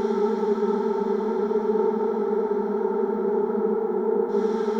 SS_CreepVoxLoopB-03.wav